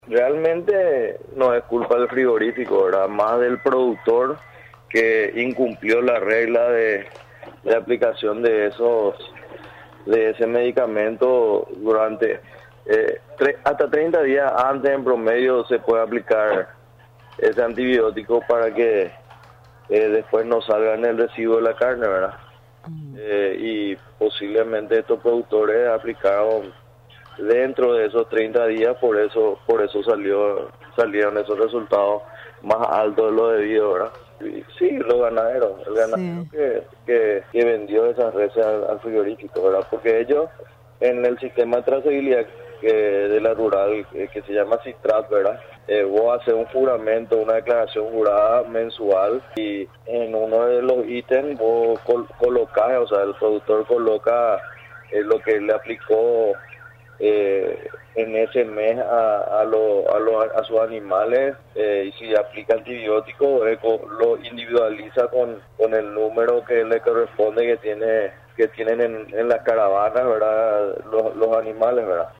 Que la carne paraguaya superara el nivel de antibiotico, permitido en el mercado ruso, incumple la regla sanitaria de aquel país, y lo coloca fuera de tiempo, explicó el titular del SENACSA, Freddis Estigarribia.